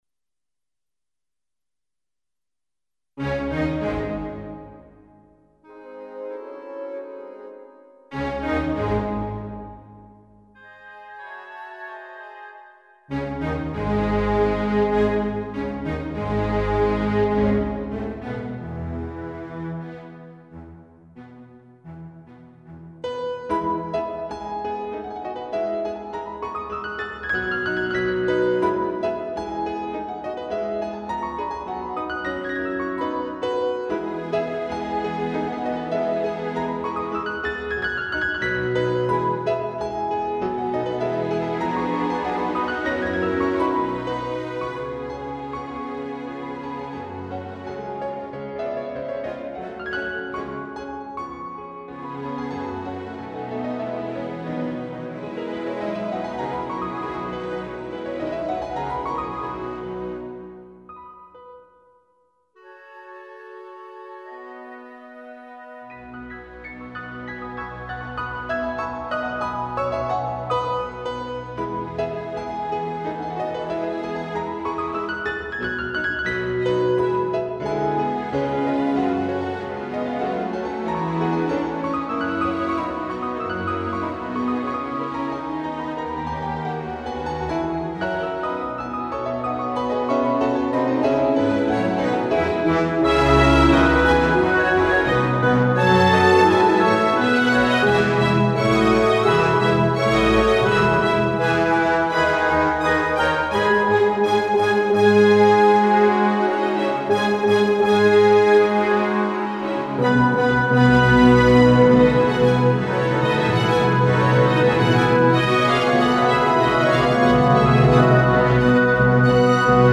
I made it with "Miroslav Philharmonik"